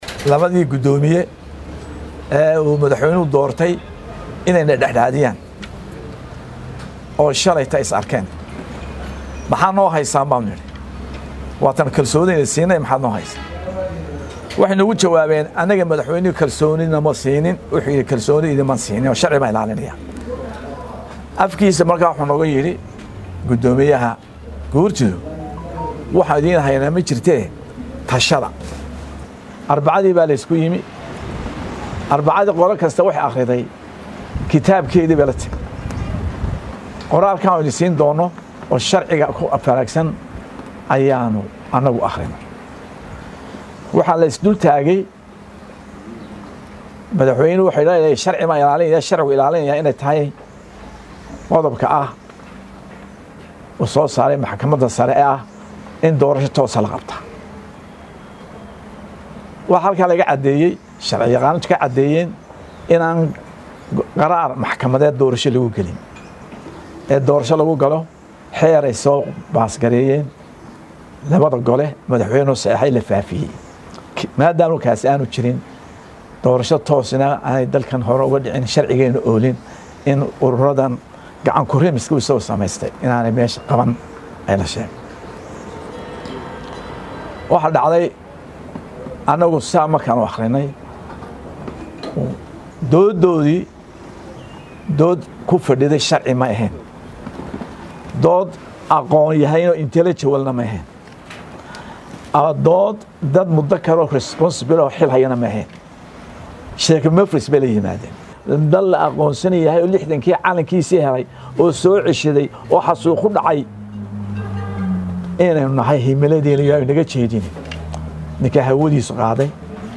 Guddoomiyayaasha Xisbiyada mucaaradka Somaliland ayaa ku dhawaaqay Maanta inay burbureen wadahadalladii u dhexeyay Xukuumadda Somaliland ee uu hogaamiyo Madaxweyne Muuse Biixi Cabdi iyo Xisbiyada mucaaradka ee UCID iyo WADANI. Shir jaraa’id oo ay Maanta si wadajir ah magaalada Hargeysa ugu qabteen Guddoomiyayaasha Xisbiyada mucaaradka ee UCID iyo WADANI ayaa waxaa ay ku eedeeyeen madaxweynaha Somaliland in uu mas’uul ka yahay inay burburaan wadahadallada Xukuumadda iyo mucaaradka.